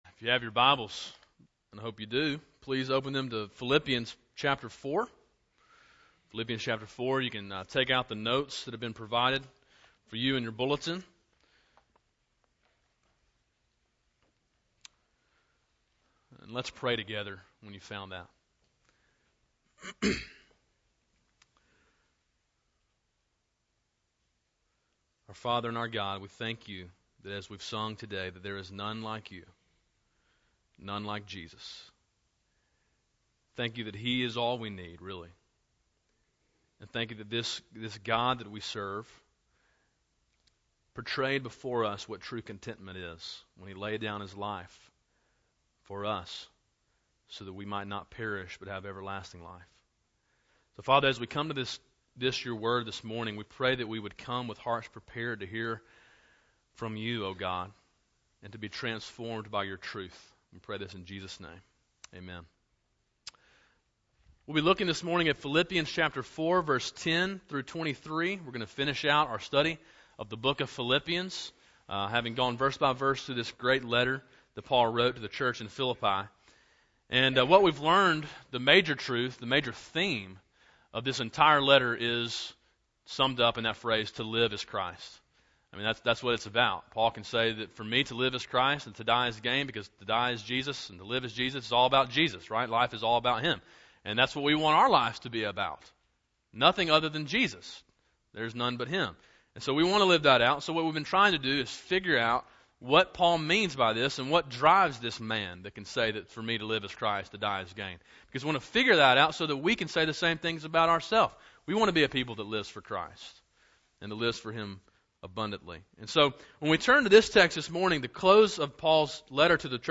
A sermon in a series entitled To Live Is Christ: Verse by Verse through the Book of Philippians. Main point: To live for Christ we must learn the secret of contentment.
november-6-2011-morning-sermon.mp3